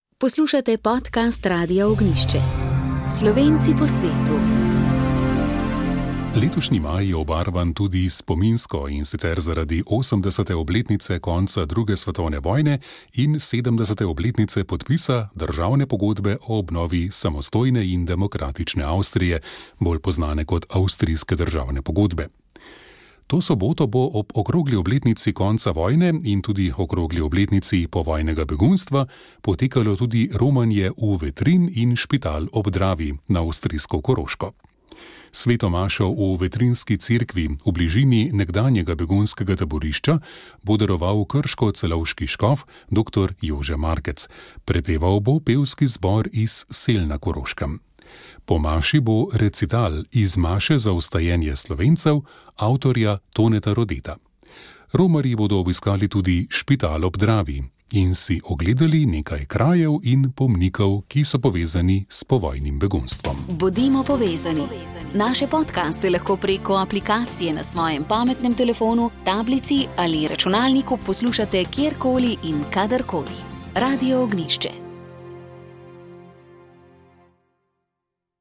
radio pandemija odnosi duhovnost komentar